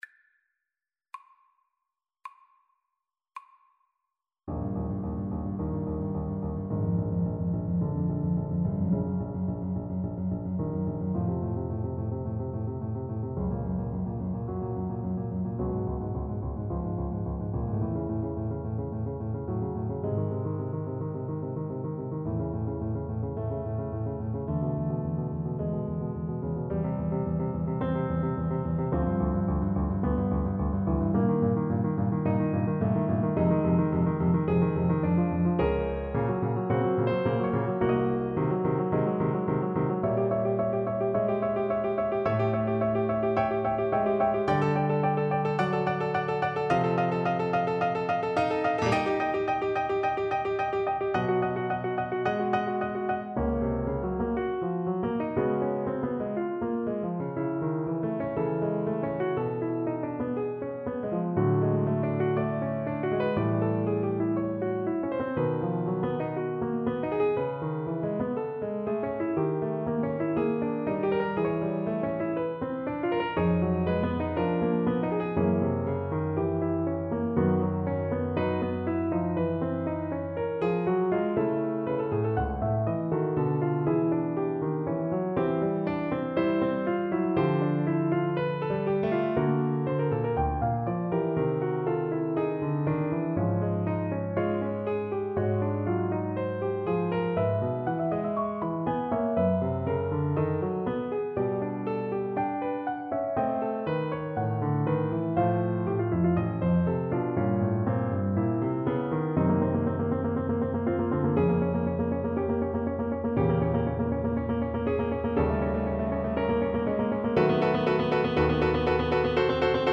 Play (or use space bar on your keyboard) Pause Music Playalong - Piano Accompaniment Playalong Band Accompaniment not yet available transpose reset tempo print settings full screen
Trombone
4/4 (View more 4/4 Music)
Eb major (Sounding Pitch) (View more Eb major Music for Trombone )
Molto moderato cominciare = c.54
Romantic music for trombone